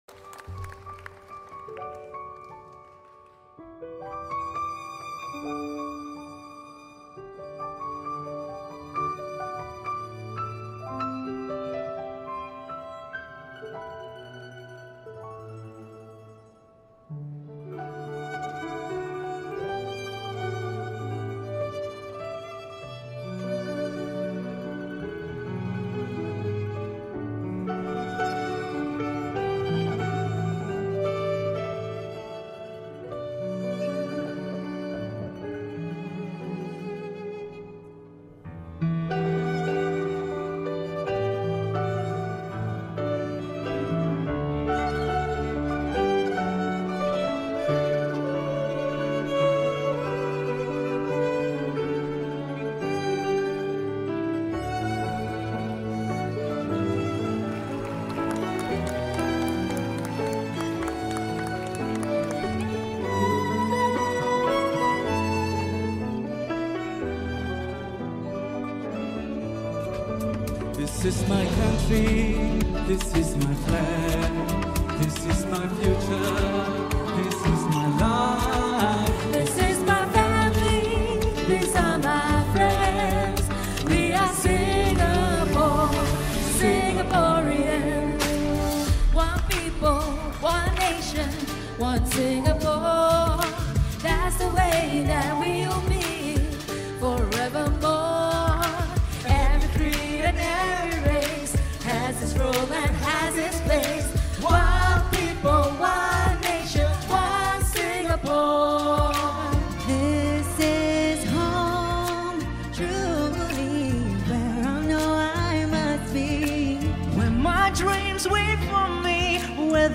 Singapore Airlines Cabin Crew performs a medley of S’pore most iconic national day songs for SG60